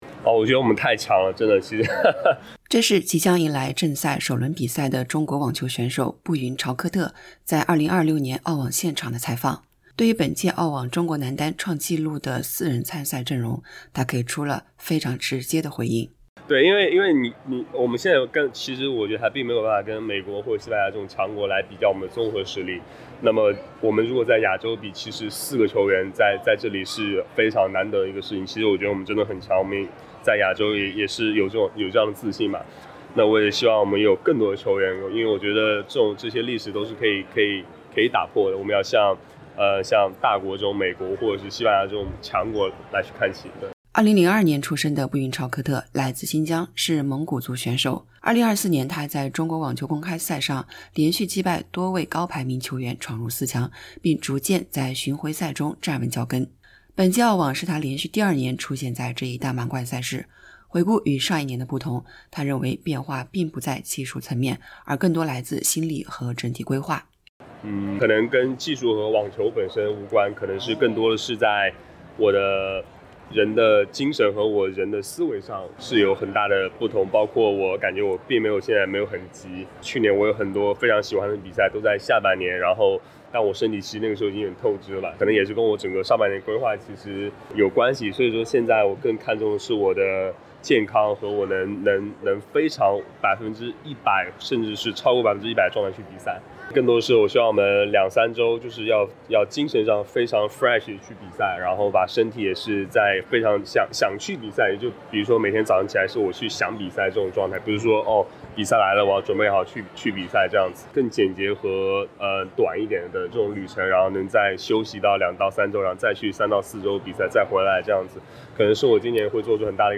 点击播放键收听完整采访 【澳网2026】中国男单四人出战澳网正赛 布云朝克特直言：“我们真的很强” SBS Chinese 03:45 Chinese 2026年澳大利亚网球公开赛（AO）将于本周日（1月18日）开启正赛。
在比赛开打前，布云朝克特在澳网现场接受采访时直接回应，笑称“我们真的很强”，并认为在亚洲范围内，四位中国男选手同时出现在澳网正赛“非常难得”。